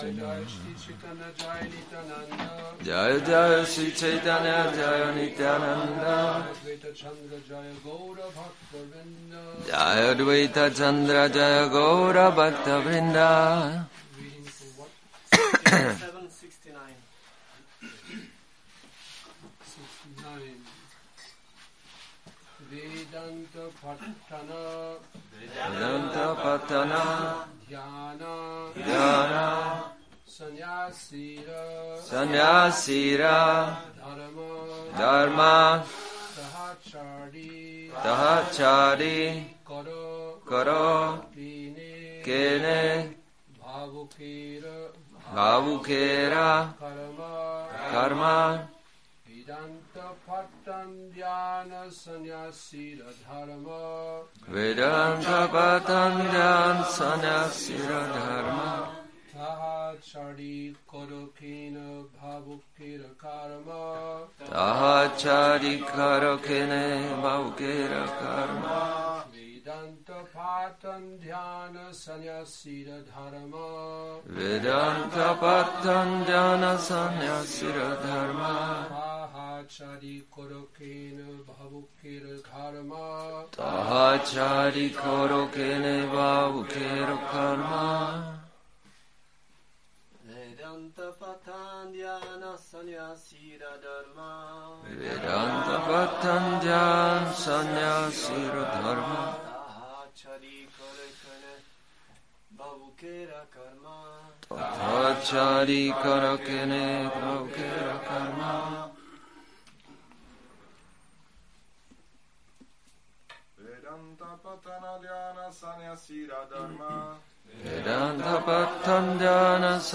Přednáška CC-ADI-7.69, Šríla Bhaktisiddhánta Sarasvatí Thákura — Odchod